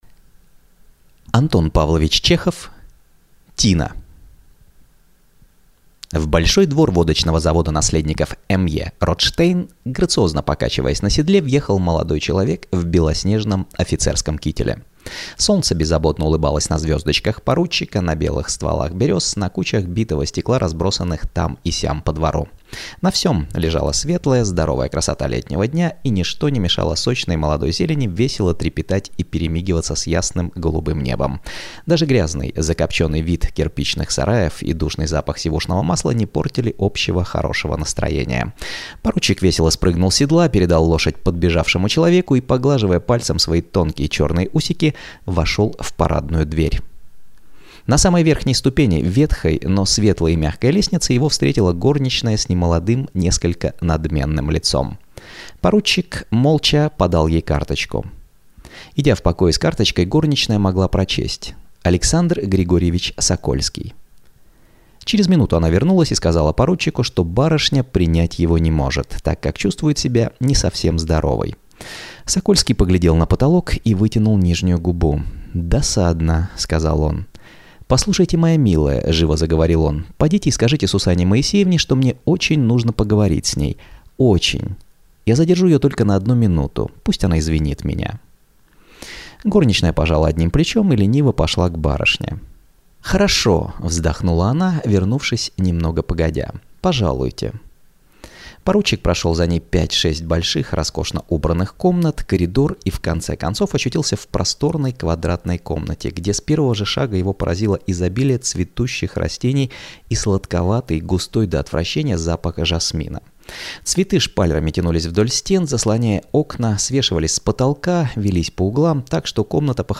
Aудиокнига Тина